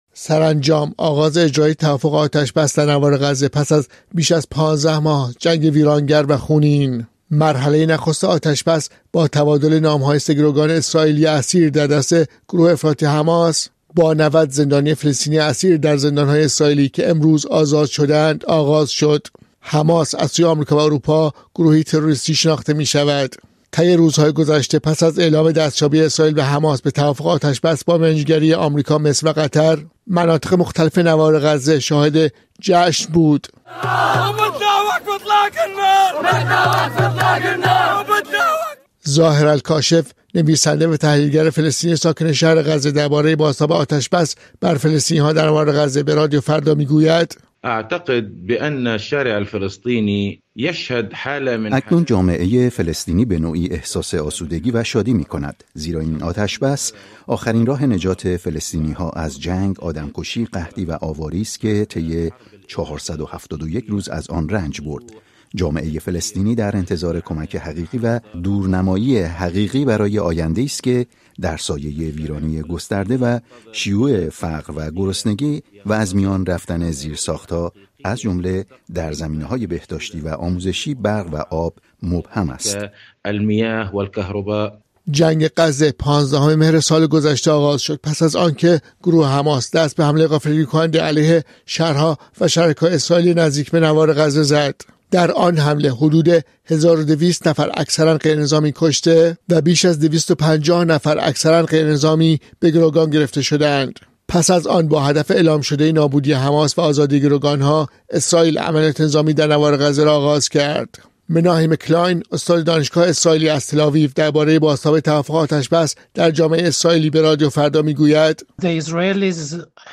این پرسش‌ها را در گزارش زیر بررسی کرده‌ایم.